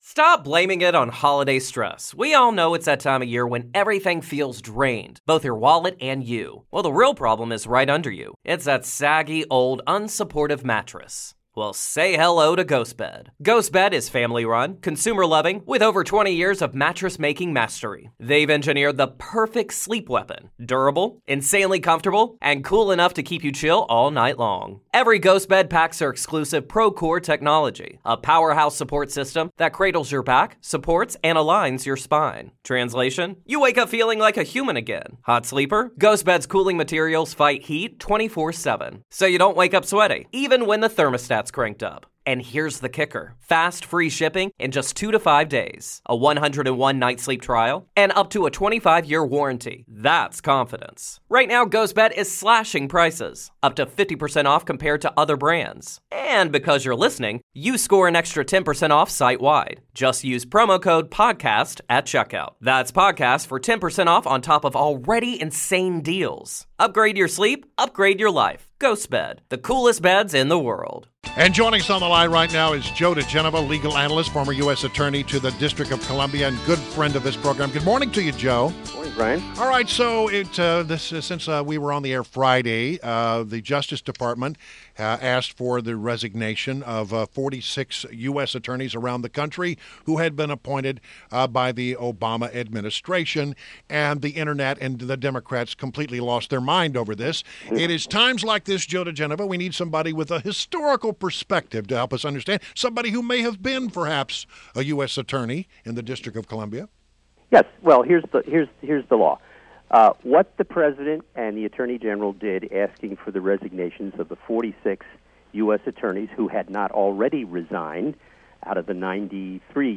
WMAL Interview - JOE DIGENOVA - 03.13.17
INTERVIEW – JOE DIGENOVA — legal analyst and former U.S. Attorney to the District of ColumbiaJustice Dept. tells remaining U.S. attorneys from Obama administration to resign, a practice common in transitions.